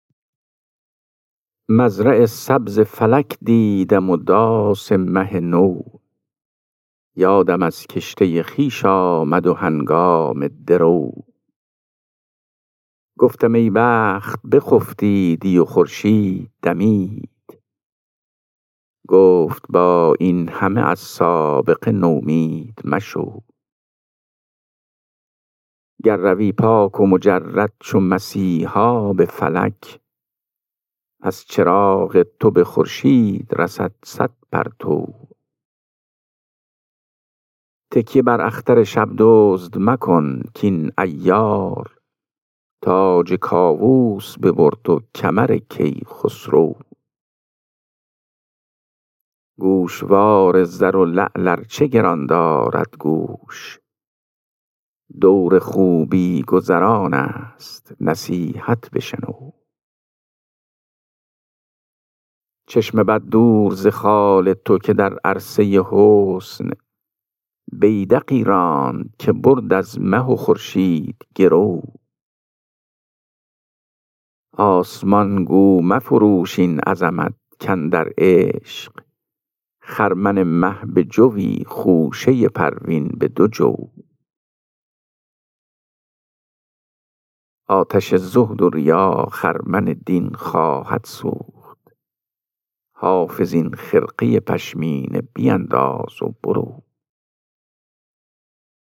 خوانش غزل شماره 407 دیوان حافظ